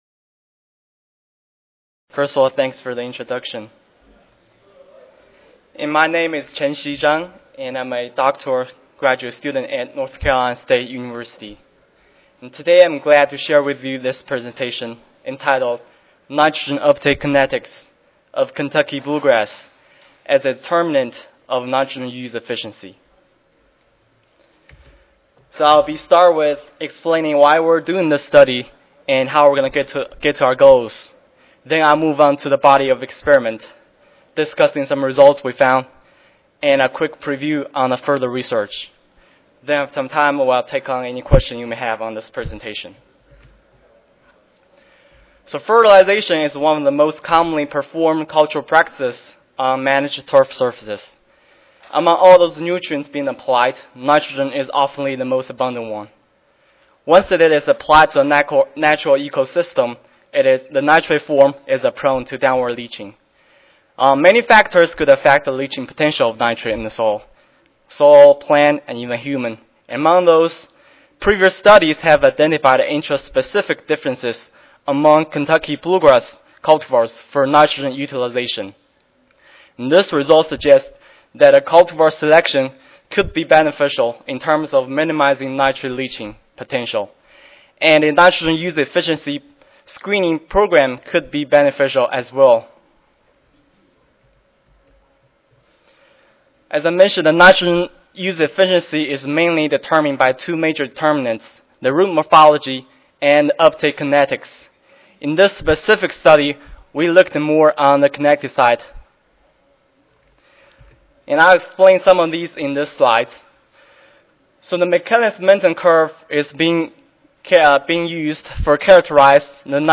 100 Student Oral Competition: Turfgrass Ecology and the Environment
Henry Gonzalez Convention Center, Room 008B
Recorded Presentation